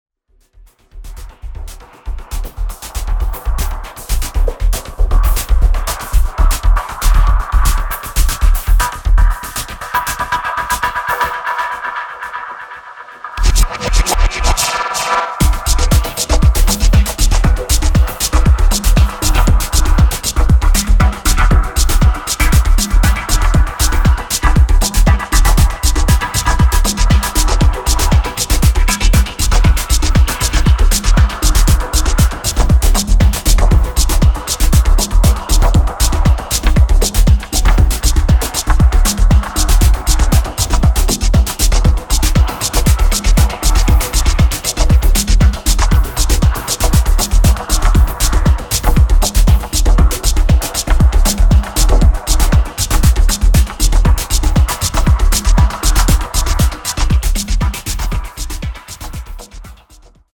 両曲ともボトムをフロア向けにマッシブに強化、うねるシンセの波で空間が捻れるようなすさまじいグルーヴ感がたまらない！
(Live in Japan)
(Live At Arma, Moscow)